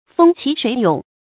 風起水涌 注音： ㄈㄥ ㄑㄧˇ ㄕㄨㄟˇ ㄩㄥˇ 讀音讀法： 意思解釋： 大風刮起，水波洶涌。